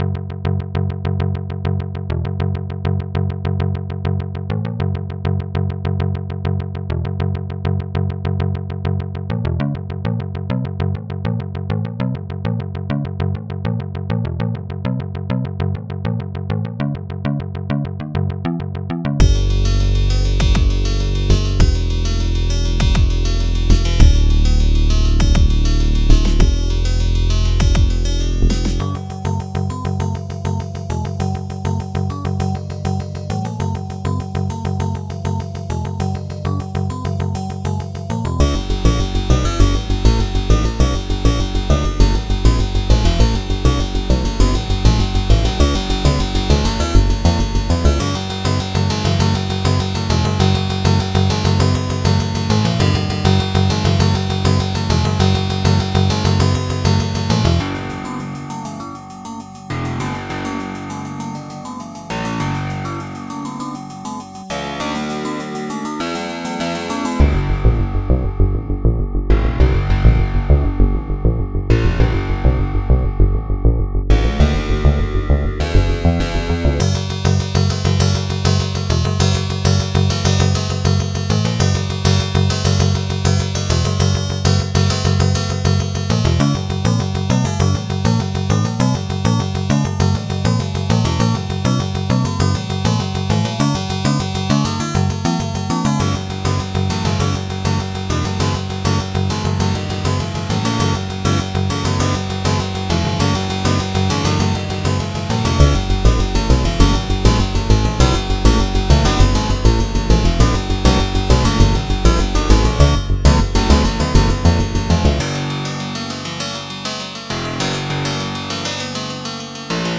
earphones/Headphones reccomended...(note : the .wav version has lower bpm, hence may sound slow)